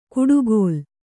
♪ kuḍugōl